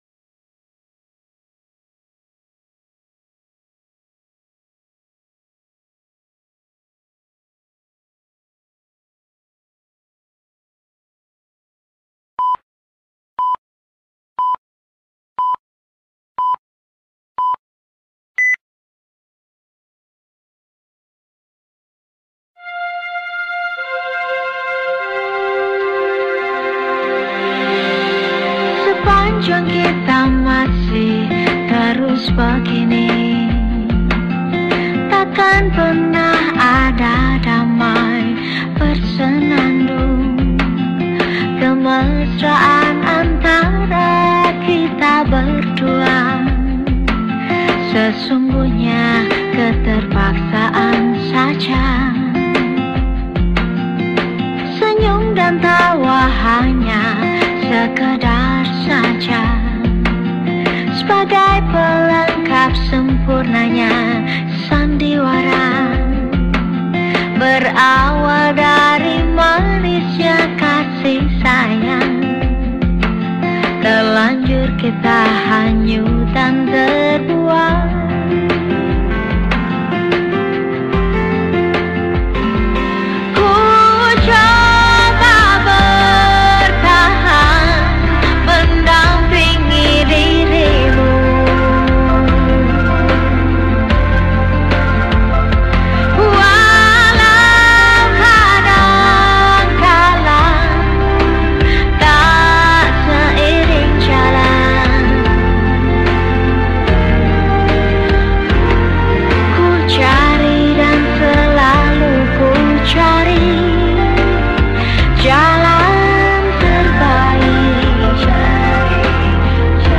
penyanyi pop